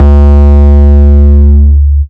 Long 808 (JW2).wav